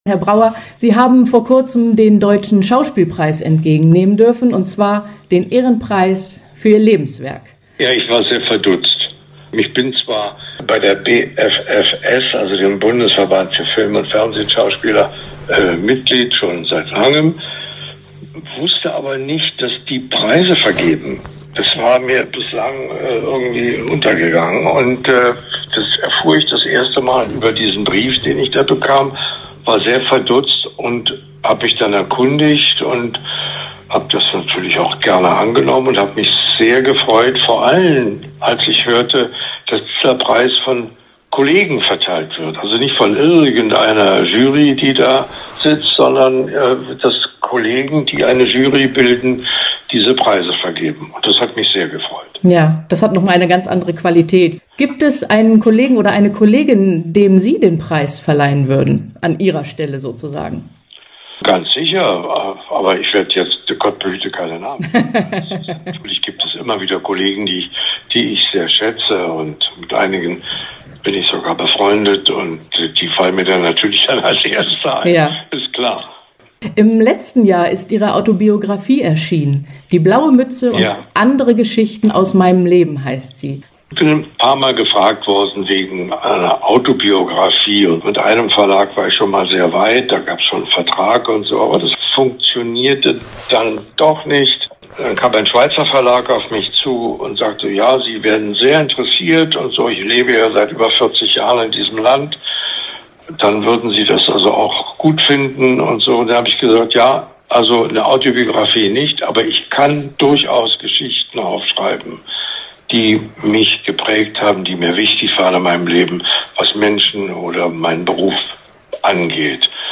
Interview-Brauer-Lesung.mp3